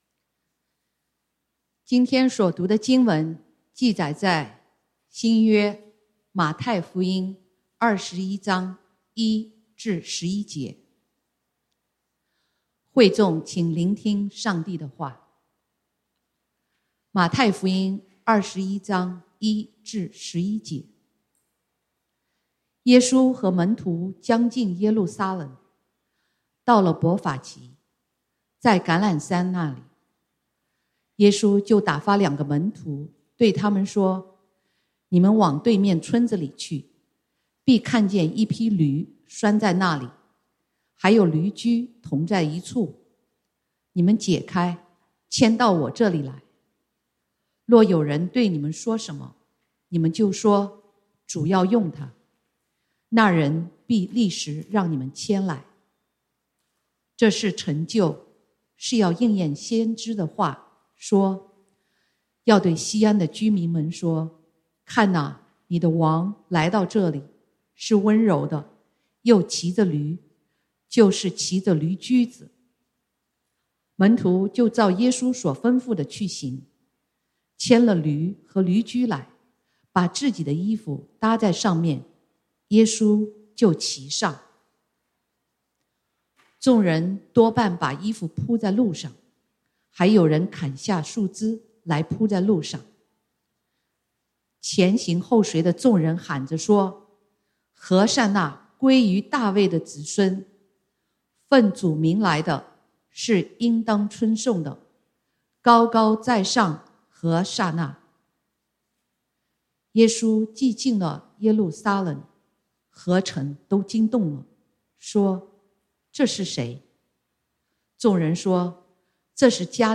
國語堂主日崇拜 講道經文：《馬太福音》Matthew 21:1-11 本週箴言：《詩篇》Psalms 95:1-3 「來啊，我們要向耶和華歌唱，向拯救我們的磐石歡呼！